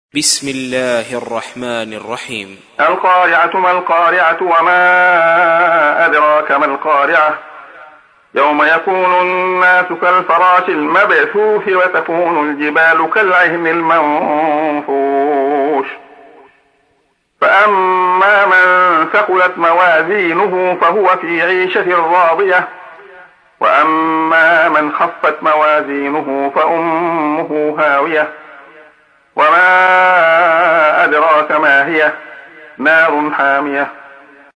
تحميل : 101. سورة القارعة / القارئ عبد الله خياط / القرآن الكريم / موقع يا حسين